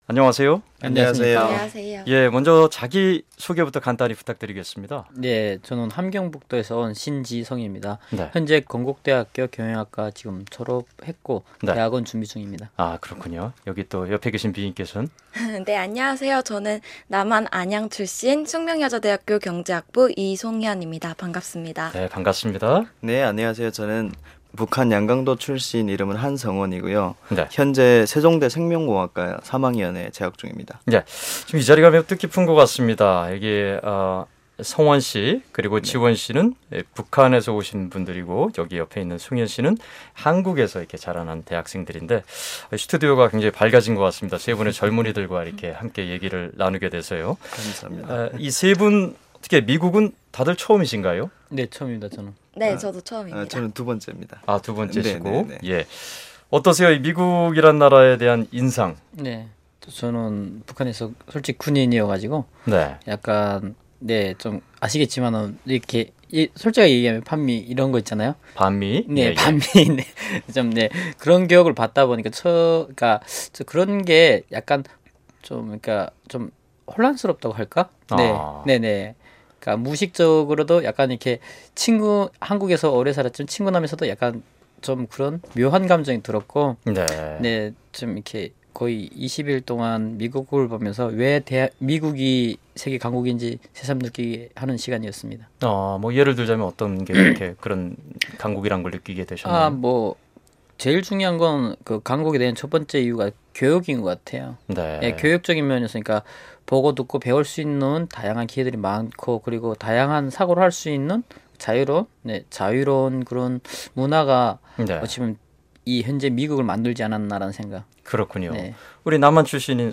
한국에서 대학에 재학 중인 탈북민 6명과 남한 출신 대학생 3명이 지난 3주 동안 미국 워싱턴과 뉴욕에서 지도자 연수를 받았습니다. VOA 본사를 방문한 학생들을 인터뷰했습니다.